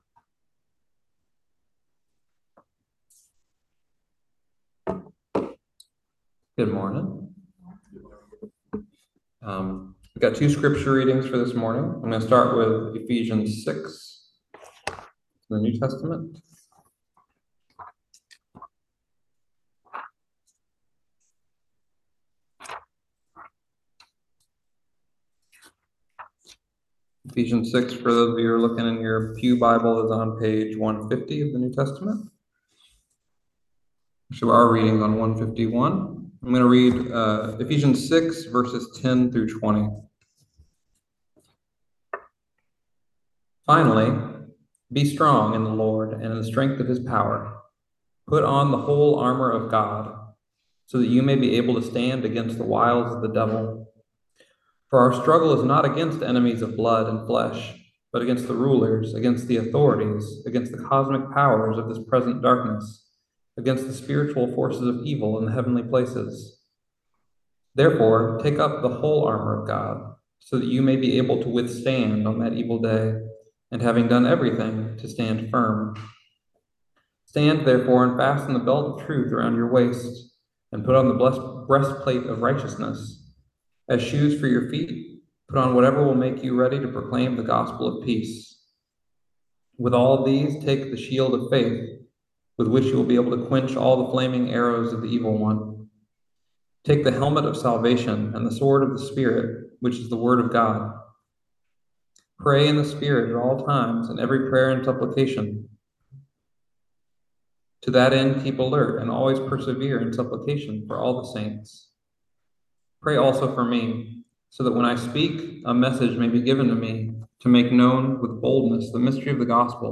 Listen to the most recent message from Sunday worship at Berkeley Friends Church, “The Armor of God.”